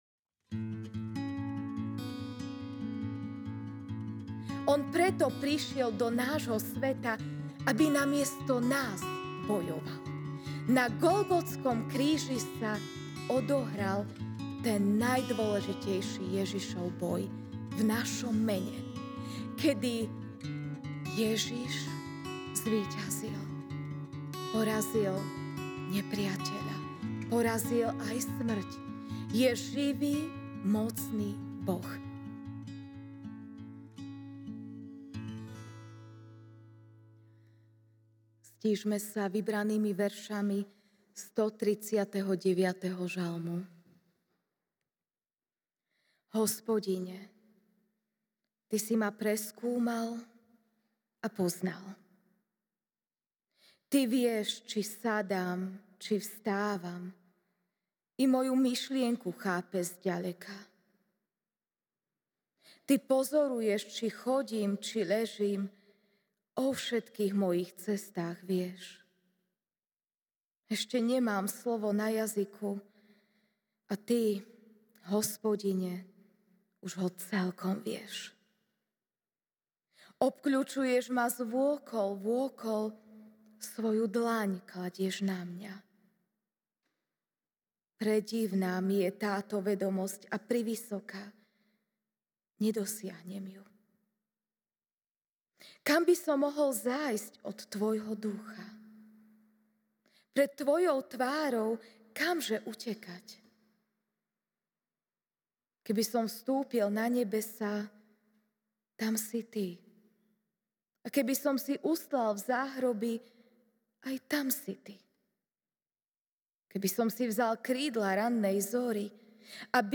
Večerná kázeň